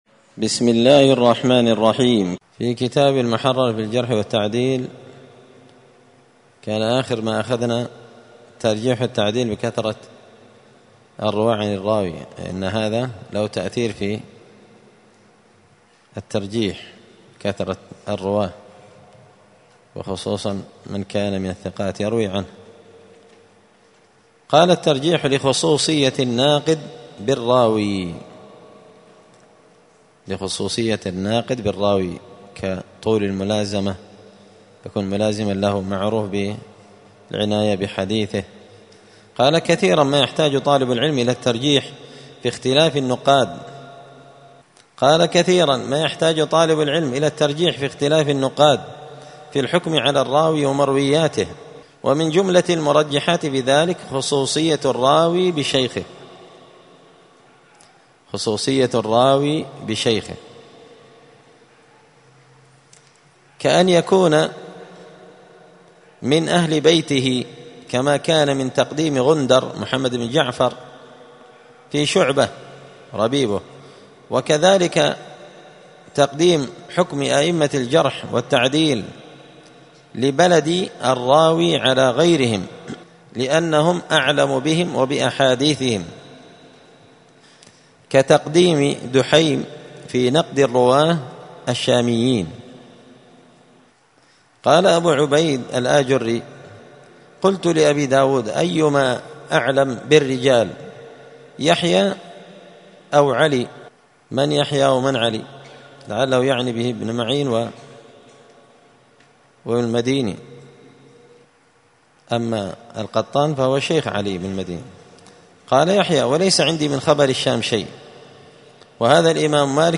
*الدرس السابع والثلاثون (37) باب الترجيح لخصوصية الناقد بالراوي*
دار الحديث السلفية بمسجد الفرقان بقشن المهرة اليمن